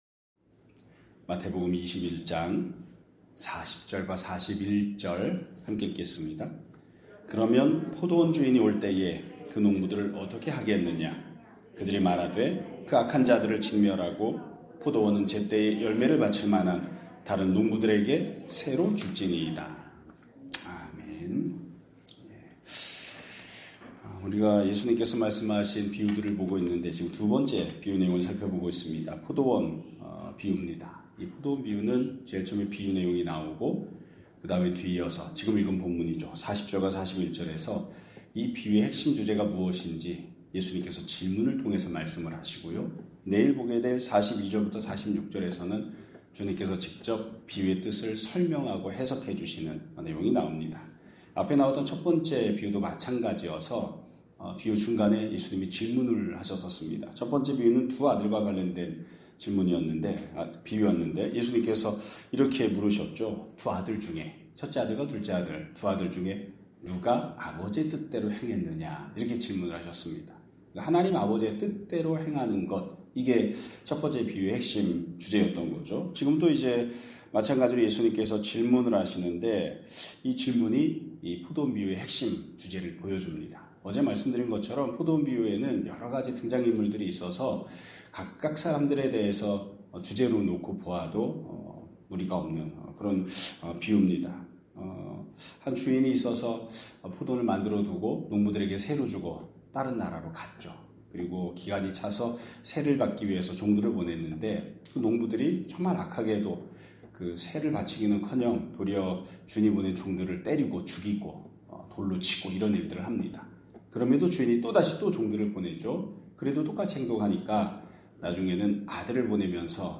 2026년 2월 4일 (수요일) <아침예배> 설교입니다.